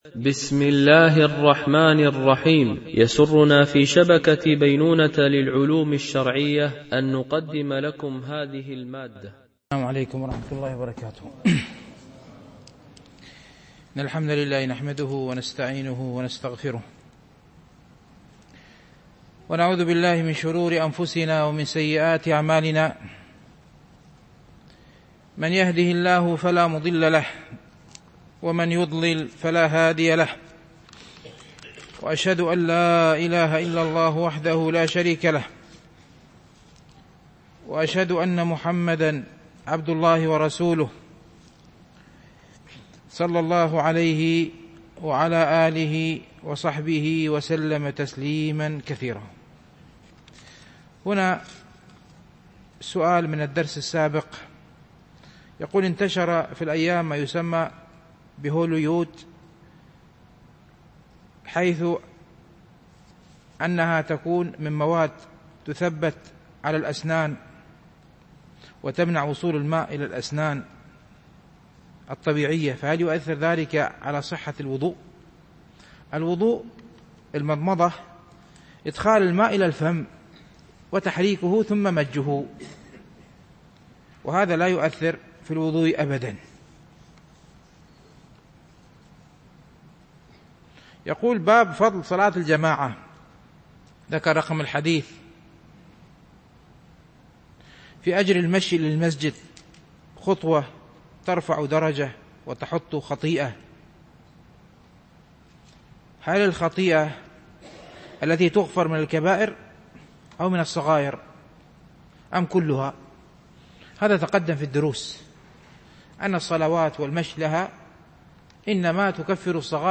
شرح رياض الصالحين – الدرس 280 ( الحديث 1075 - 1077 )